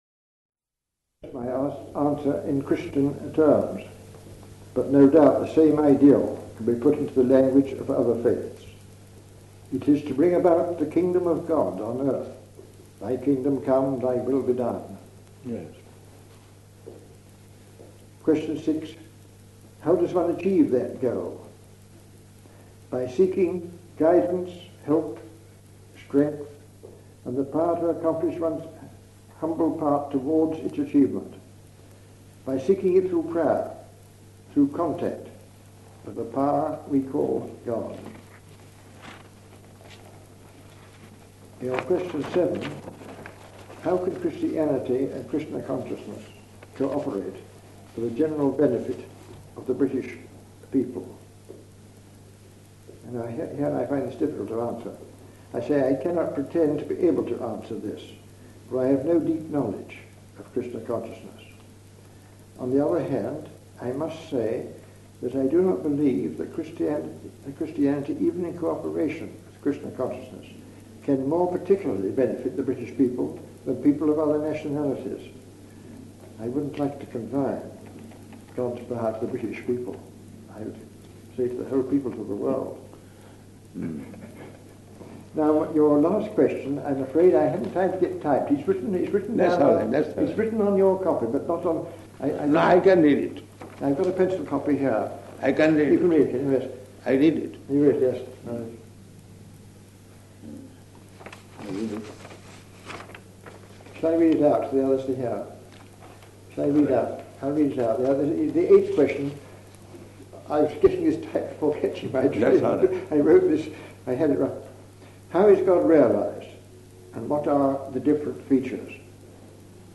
Conversation with Sir Alistair Hardy
Type: Conversation
Location: London